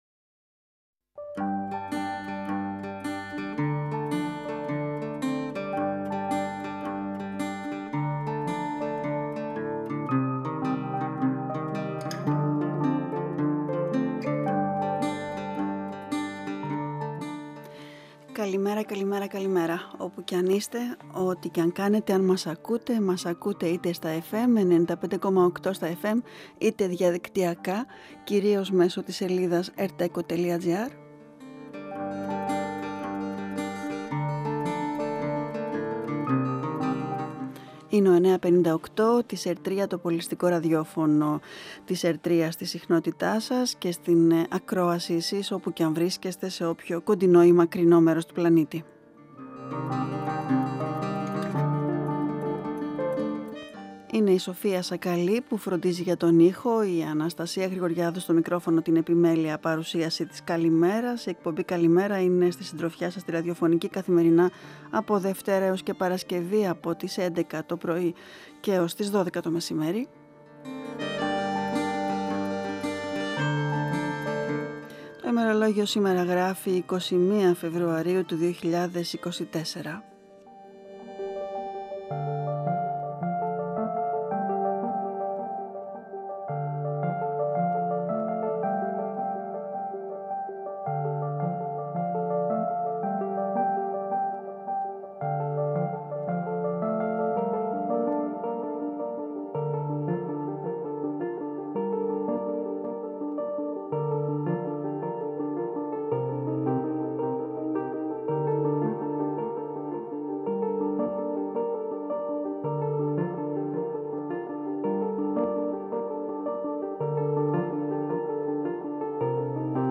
Συνέντευξη
Η συνέντευξη πραγματοποιήθηκε την Τετάρτη 21/2/2024 εκπομπή “καλημέρα” στον 9,58fm της ΕΡΤ3